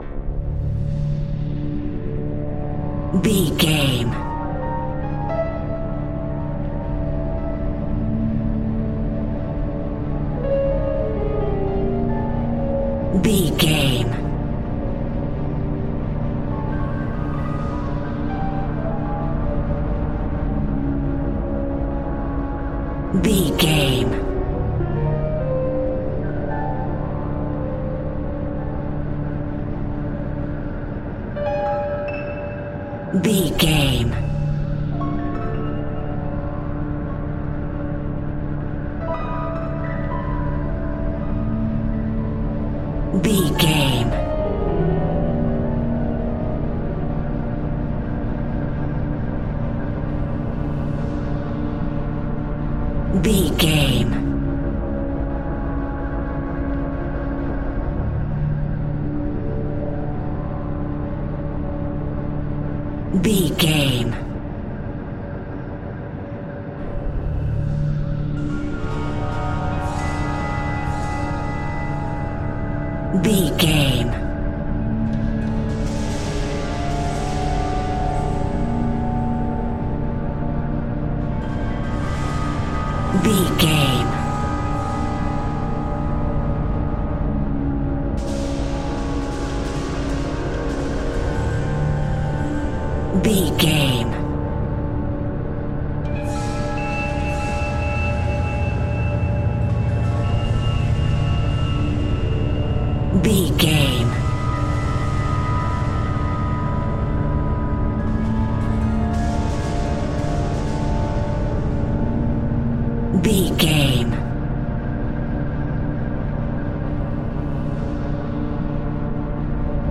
Atonal
Slow
ominous
eerie
piano
horror music
Horror Pads
Horror Synths